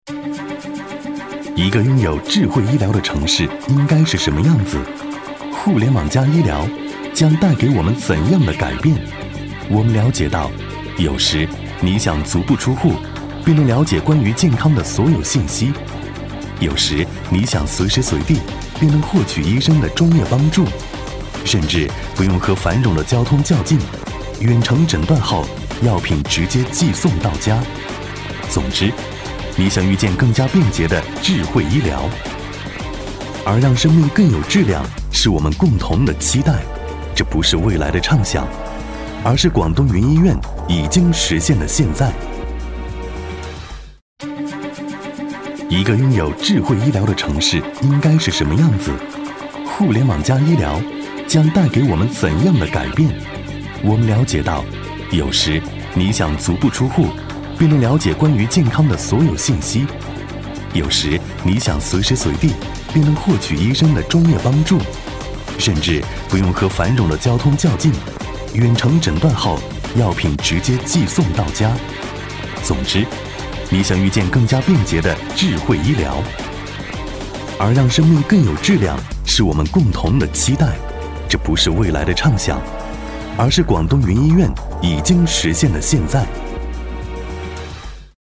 男S356 国语 男声 专题片-互联网医疗-科技、节奏感 大气浑厚磁性|沉稳